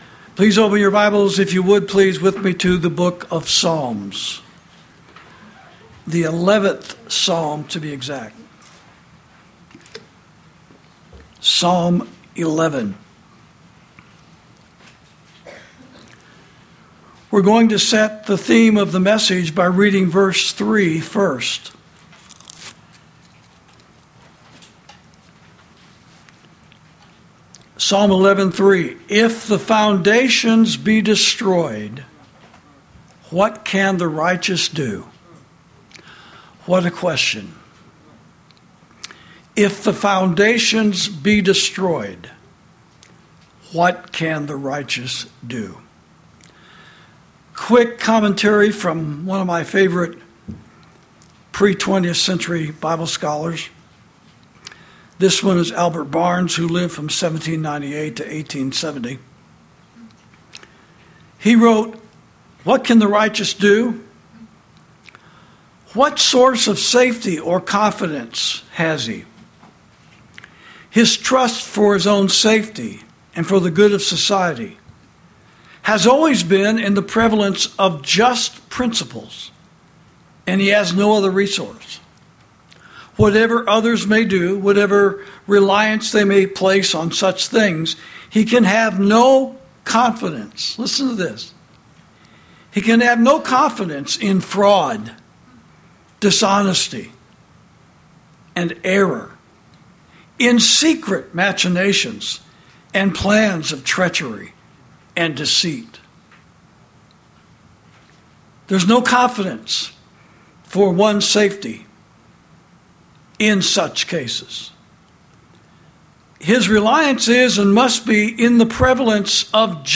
Sermons > What To Do When The Foundations Are Destroyed